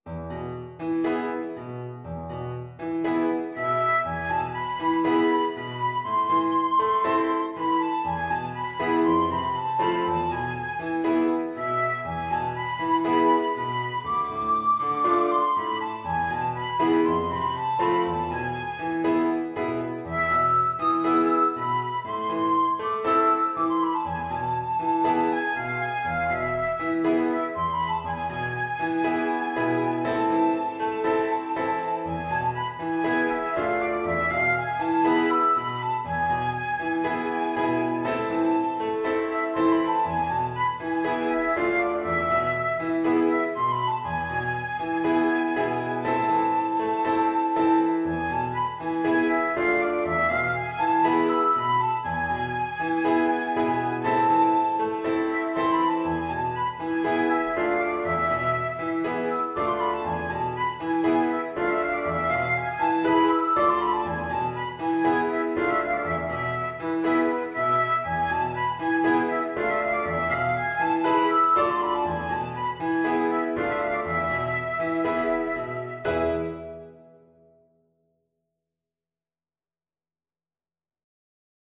Traditional Trad. Araber Tanz (Klezmer) Flute version
Flute
4/4 (View more 4/4 Music)
A minor (Sounding Pitch) (View more A minor Music for Flute )
Allegro moderato =120 (View more music marked Allegro)
Traditional (View more Traditional Flute Music)